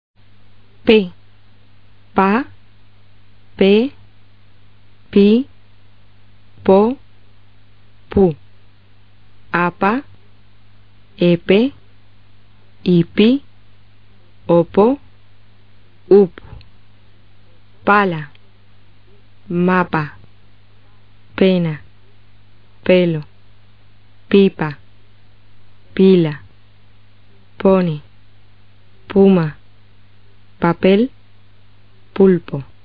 P 的名称是pe，音标是[pe]，为双唇塞清辅音，注意，它可不是汉语拼音中P的发音，而类似于B。
P的发音：